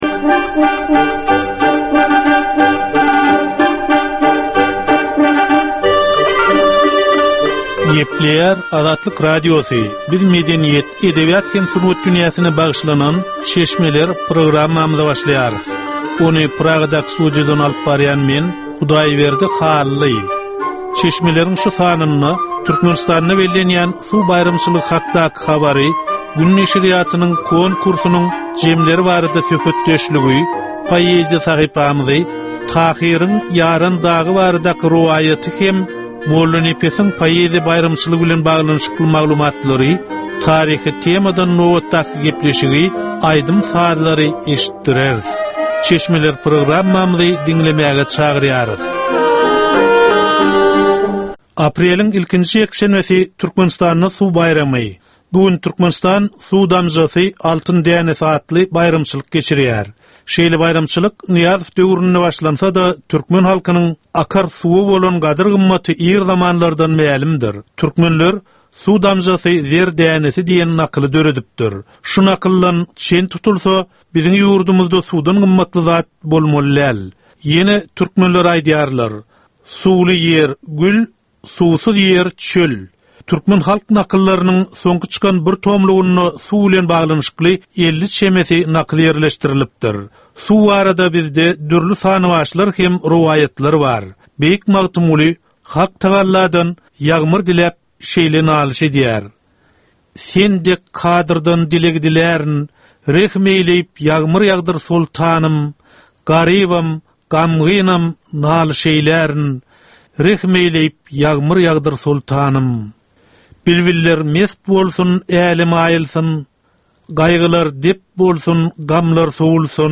Edebi, medeni we taryhy temalardan 25 minutlyk ýörite gepleşik.